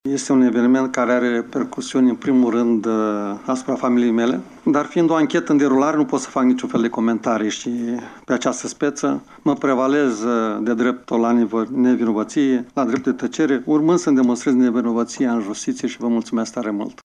După şedinţa extraordinară de astăzi a Consiliului Judeţean Iaşi, Maricel Popa nu şi-a înaintat demisia din funcţia de preşedinte şi îşi susţine în continuare nevinovăţia în dosarul în care este cercetat pentru trei infracţiuni de abuz în serviciu: